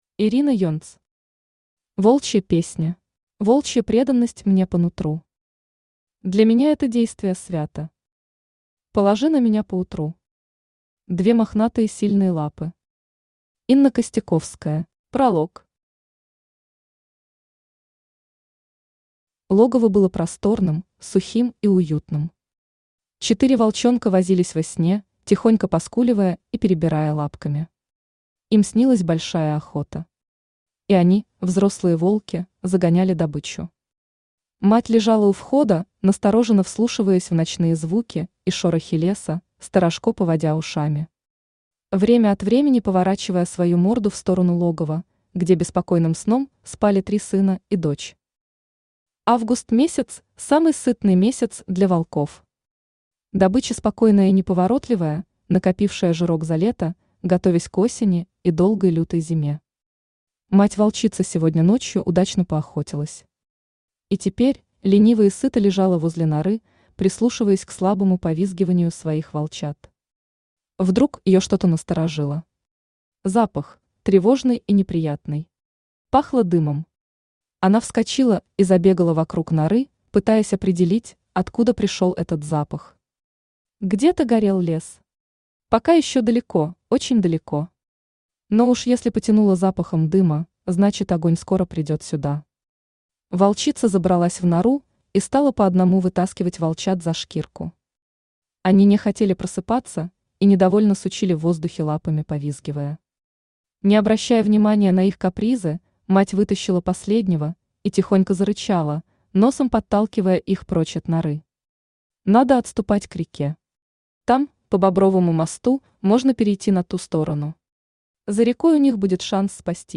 Аудиокнига Волчья песня | Библиотека аудиокниг
Aудиокнига Волчья песня Автор Ирина Юльевна Енц Читает аудиокнигу Авточтец ЛитРес.